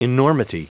Prononciation du mot enormity en anglais (fichier audio)
Prononciation du mot : enormity
enormity.wav